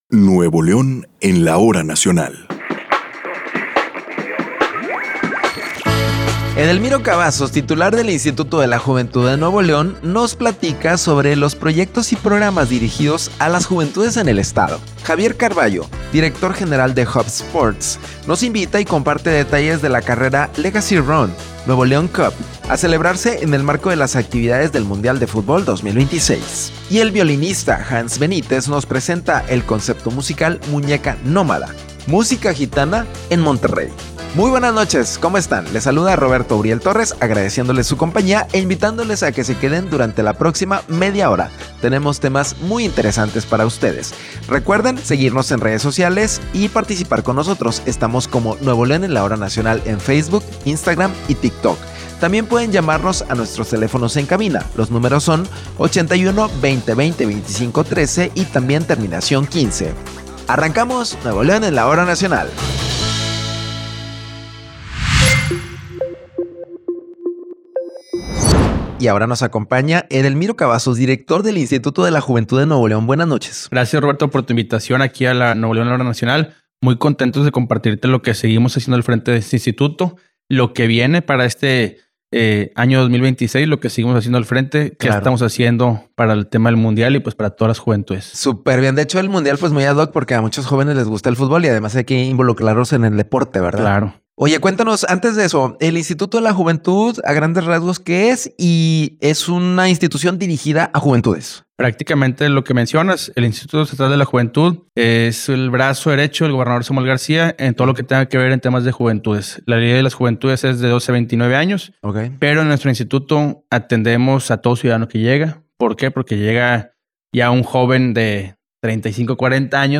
En la emisión del 12 de abril de 2026: Edelmiro Cavazos, titular del Instituto Estatal de la Juventud, nos cuenta acerca de los programas dirigidos a las juventudes de Nuevo León.
música gitana en Monterrey. Escucha y descarga aquí Video Mira aquí la versión completa en video del programa del 5 de abril de 2026 que se transmitió por redes sociales y Radio.